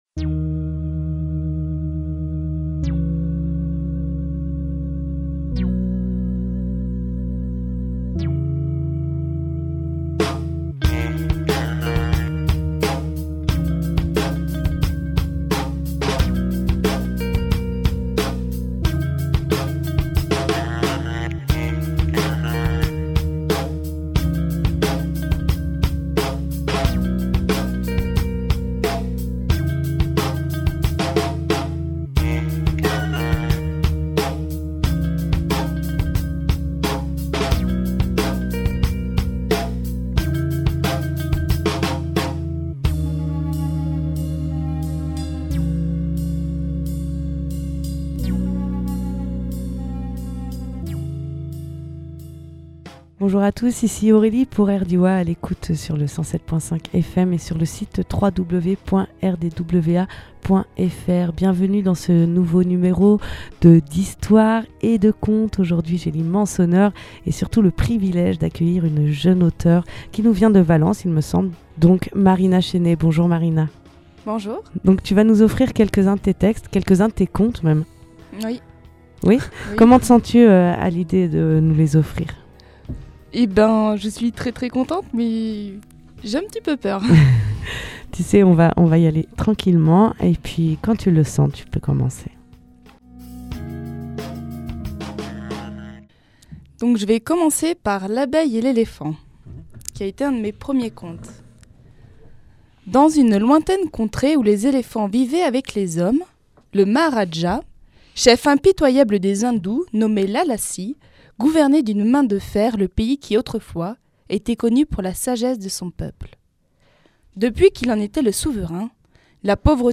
Lieu : Studio Rdwa